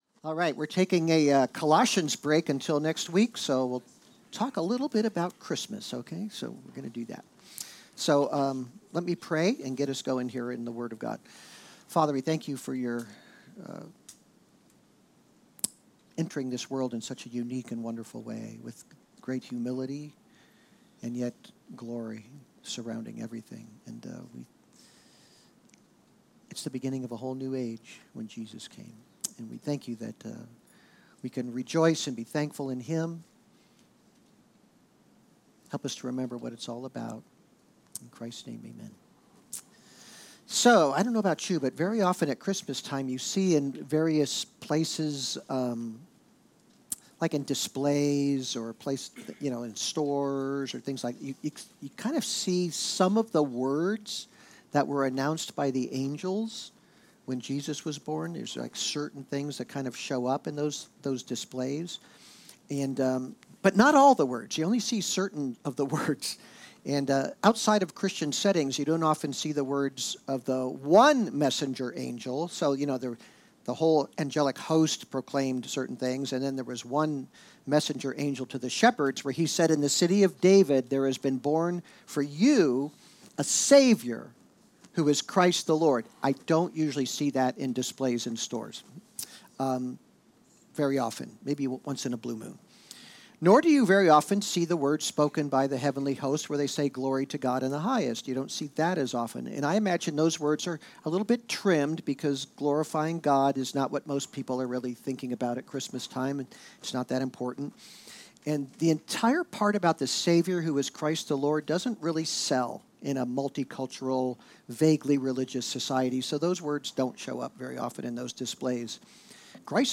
Worship Watch Listen Save Because Jesus was born, peace has come. In this pre-Christmas sermon